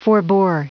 Prononciation du mot forbore en anglais (fichier audio)
Prononciation du mot : forbore